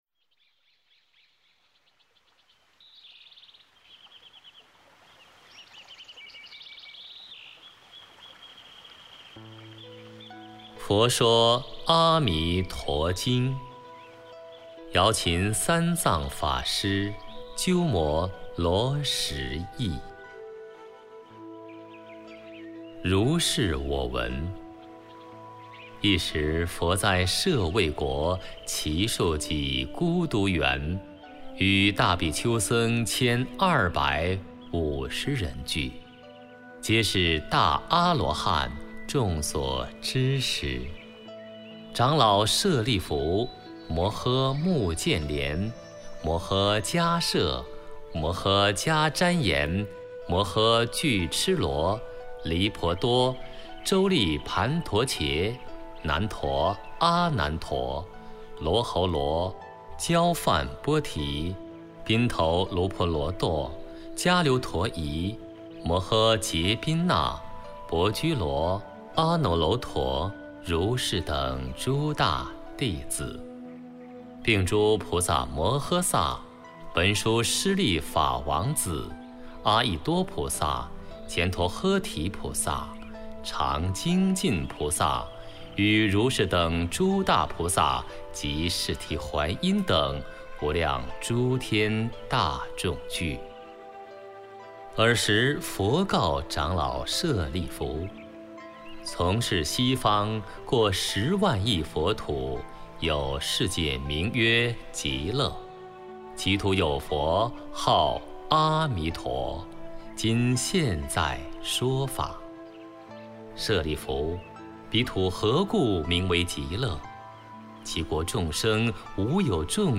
阿弥陀经读诵（本地音频） - 佛乐诵读
amituojing-songdu.mp3